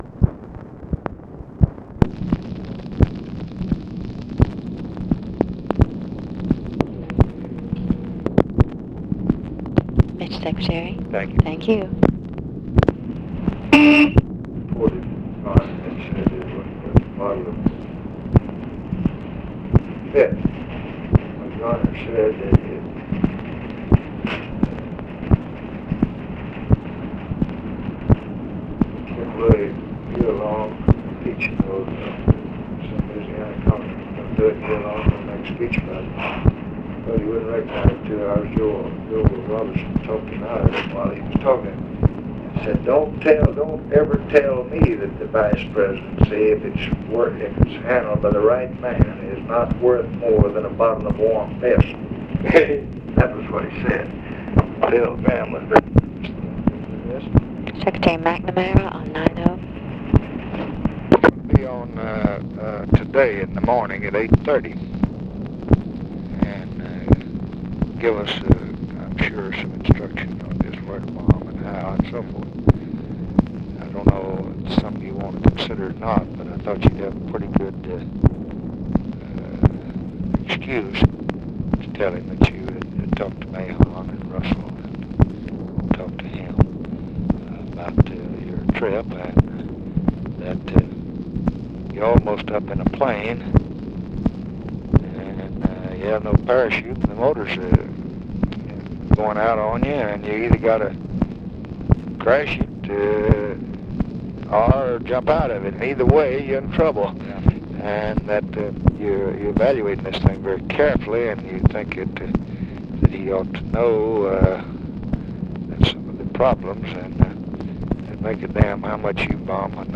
Conversation with ROBERT MCNAMARA and OFFICE CONVERSATION, July 8, 1965
Secret White House Tapes